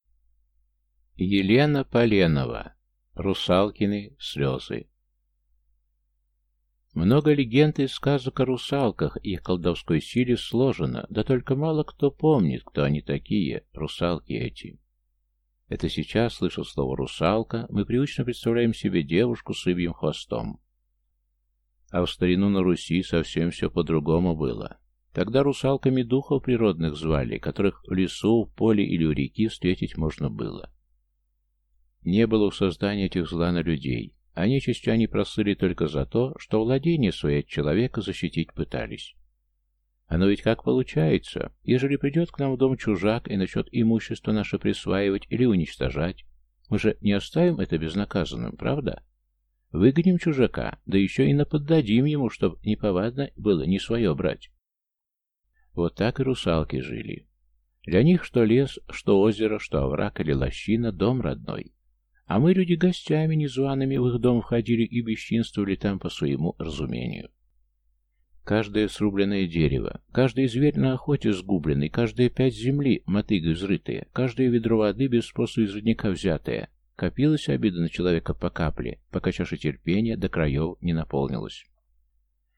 Аудиокнига Русалкины слёзы | Библиотека аудиокниг
Прослушать и бесплатно скачать фрагмент аудиокниги